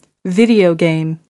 19 video game (n) /ˈvɪdiəʊ ɡeɪm/ Trò chơi điện tử